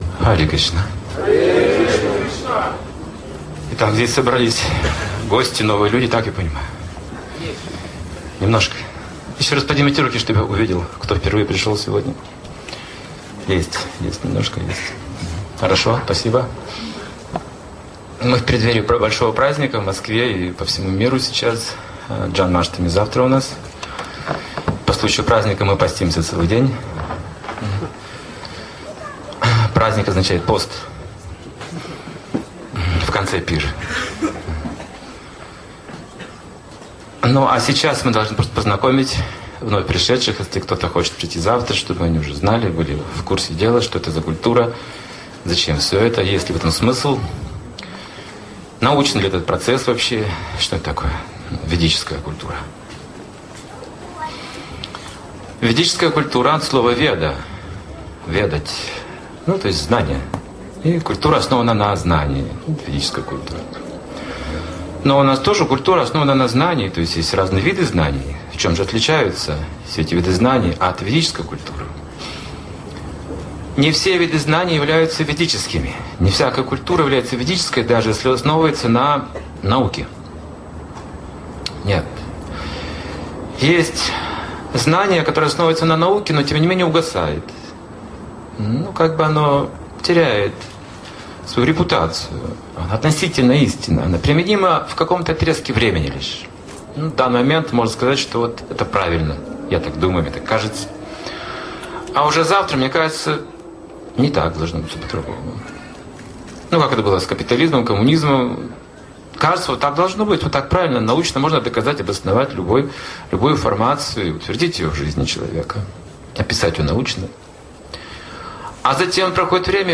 Настоящая духовная наука (2009, Москва)